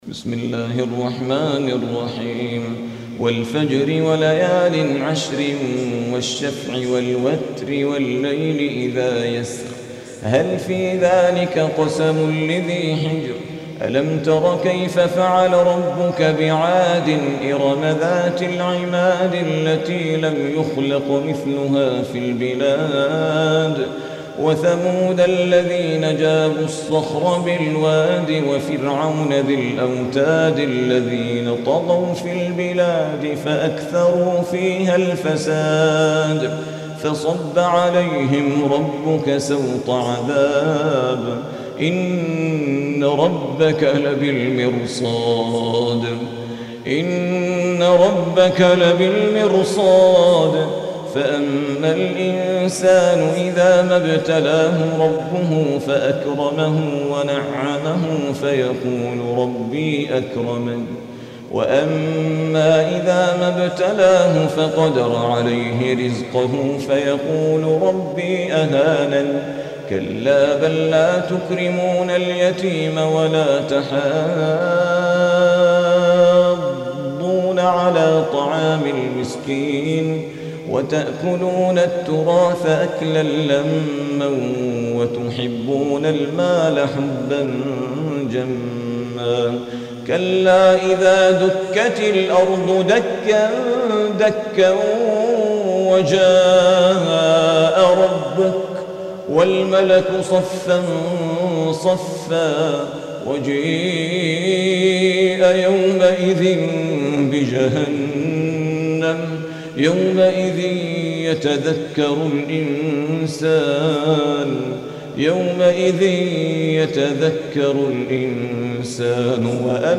Audio Quran Tarteel Recitation